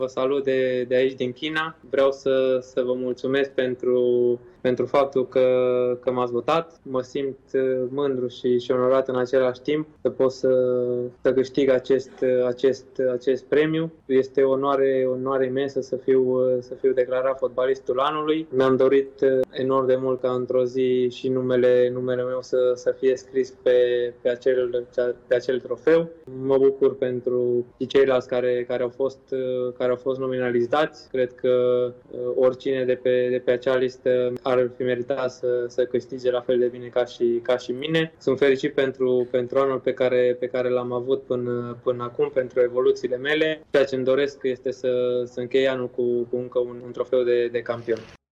Laureatul Nicolae Stanciu a transmis un mesaj special din China.